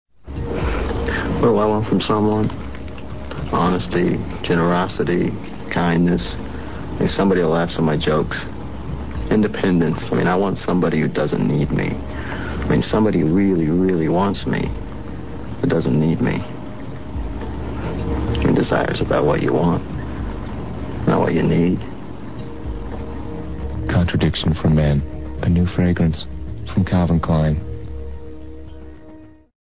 译文：（一男子坐在那时进行自我表白。）